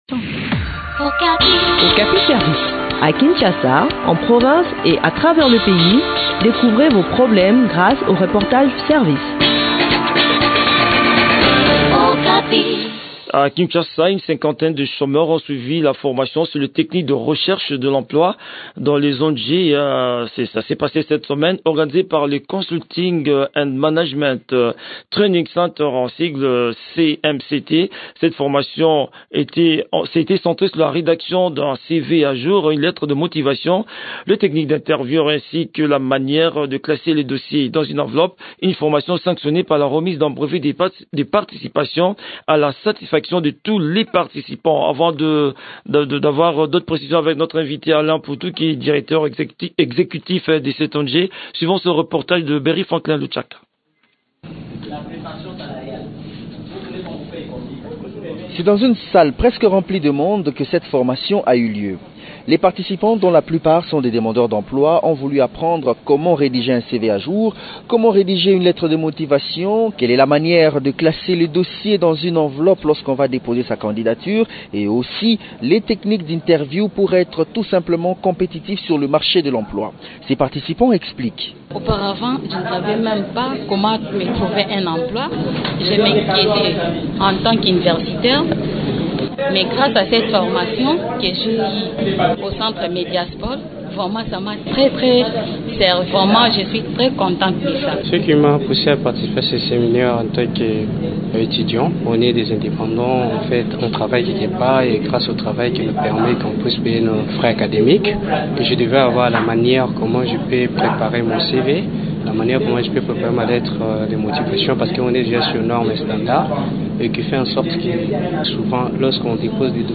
Le point sur l’organisation de cette session de formation dans cet entretien